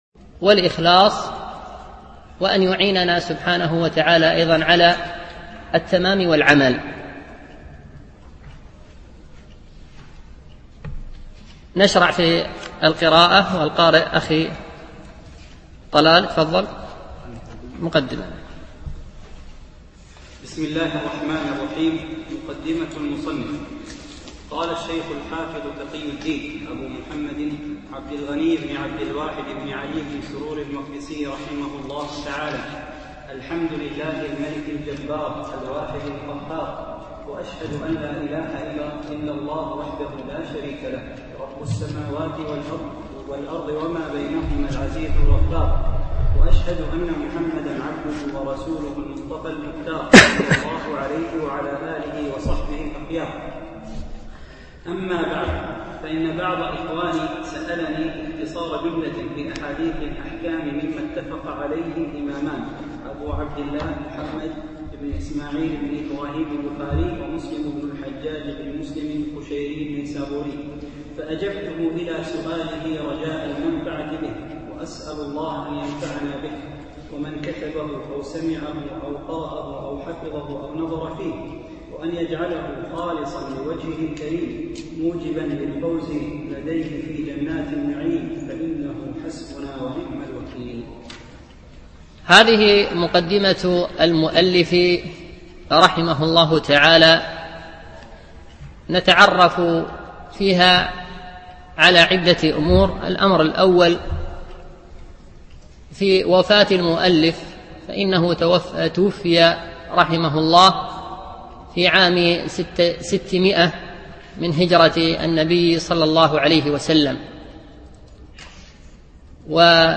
شرح عمدة الأحكام ـ الدرس الأول